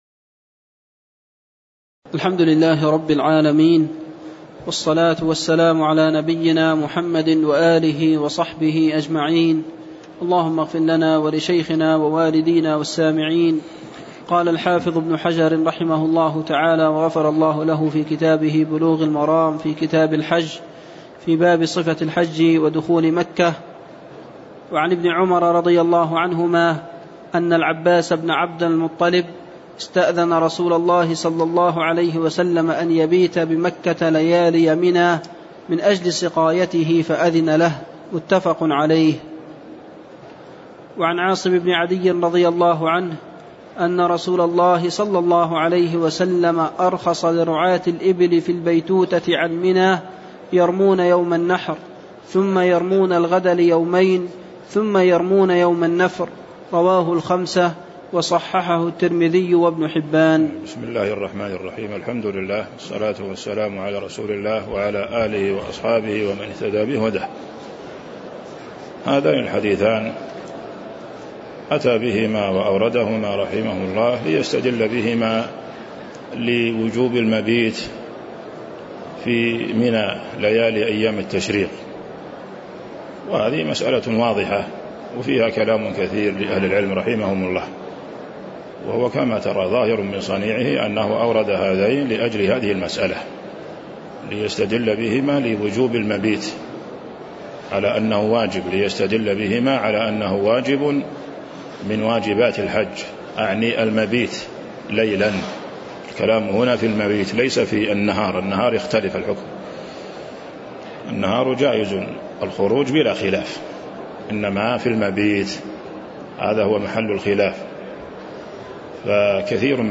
تاريخ النشر ١ ذو الحجة ١٤٤٣ هـ المكان: المسجد النبوي الشيخ